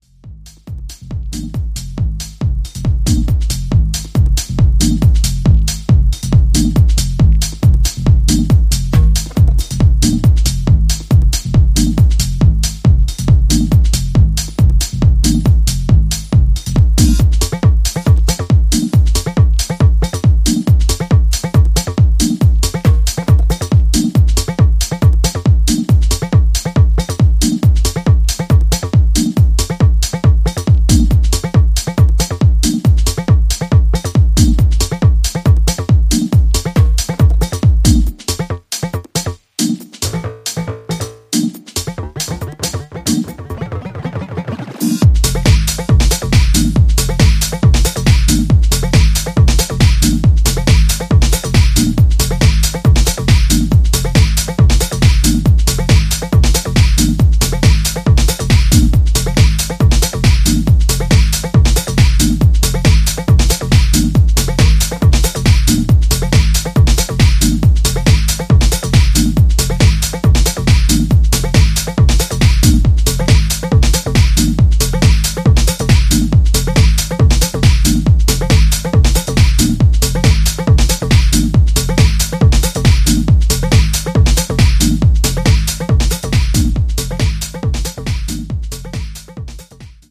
前傾姿勢のベースラインを基調にミニマルにまとめたBPM138のテック・ハウス・グルーヴァー